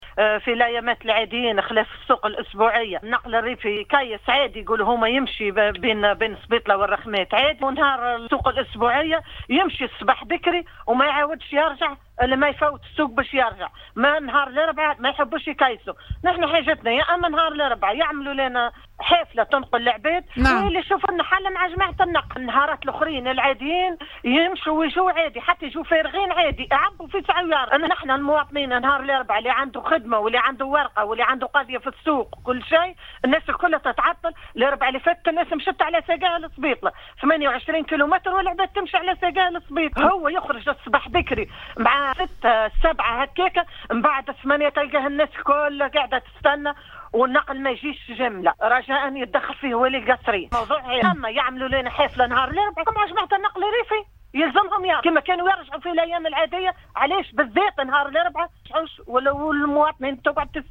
إحدى متساكنات المنطقة تتحدث  في التسجيل التالي: